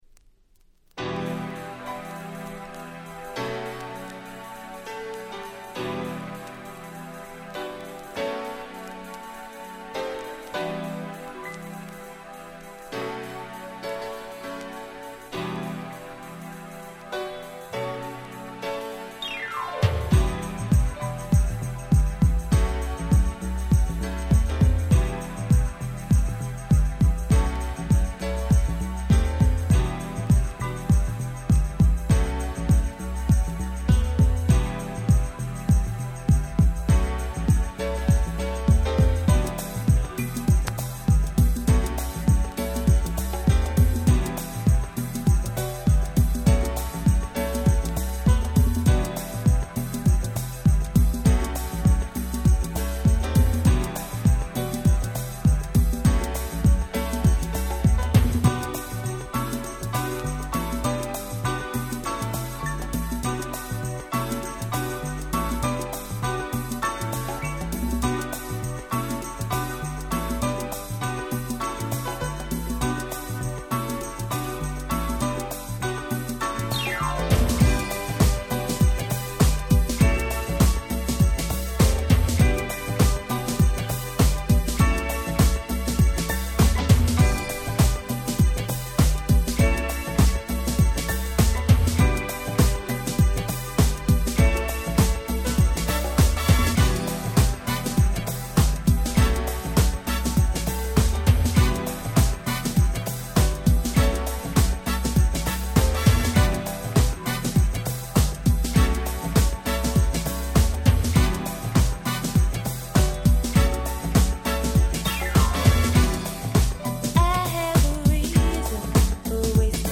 91' Nice UK Soul !!
彼お得意のPianoの旋律が素晴らしく美しい、筆舌し難い最高のUK Soulに仕上がっております。